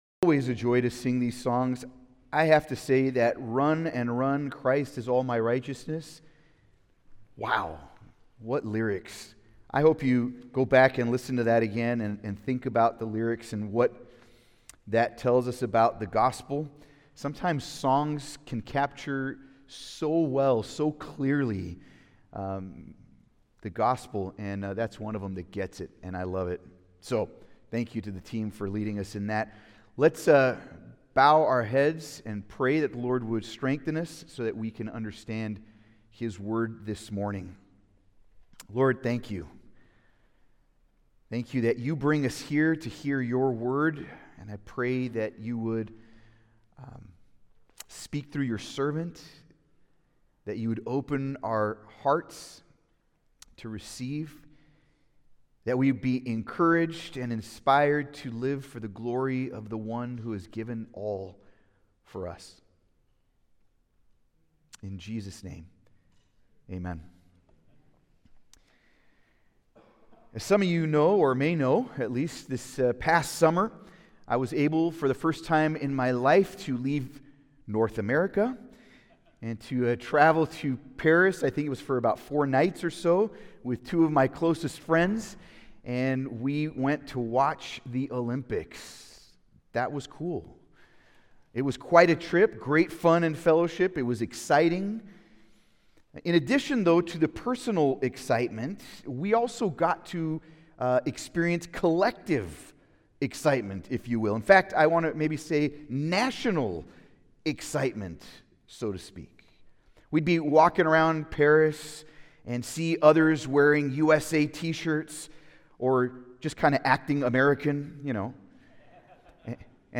Passage: Philippians 1:27-30 Service Type: Sunday Service